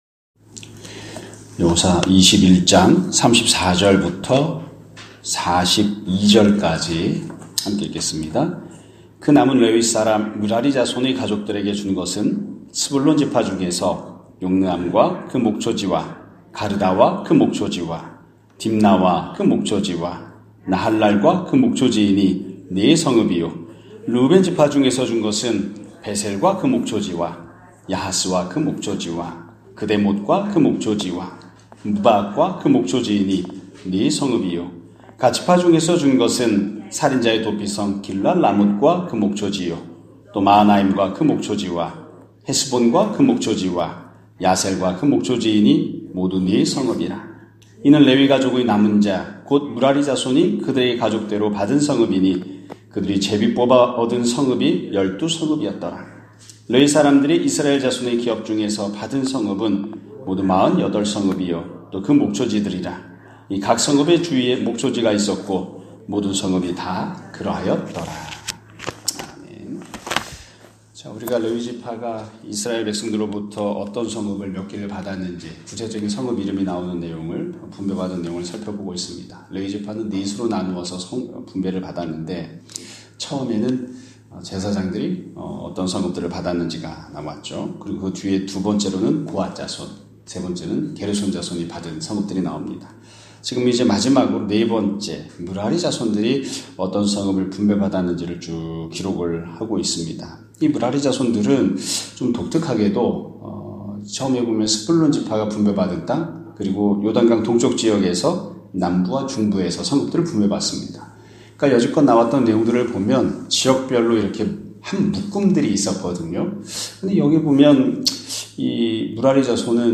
2025년 1월 24일(금요일) <아침예배> 설교입니다.